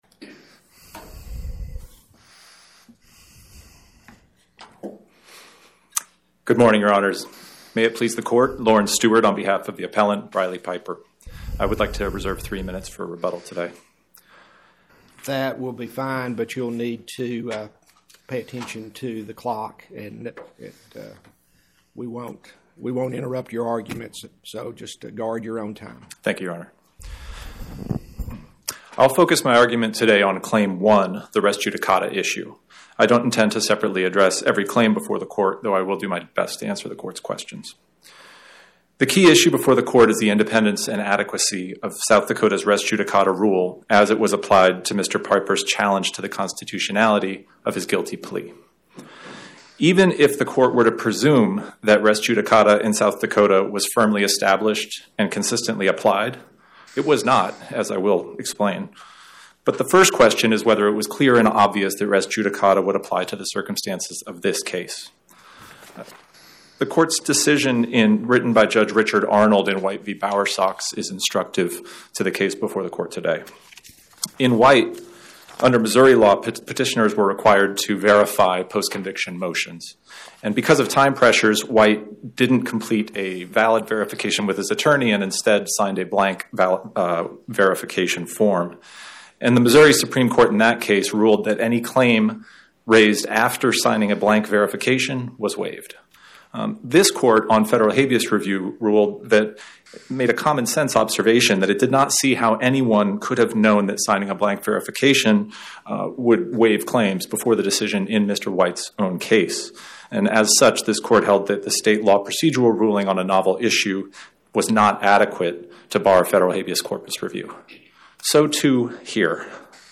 Oral argument argued before the Eighth Circuit U.S. Court of Appeals on or about 03/18/2026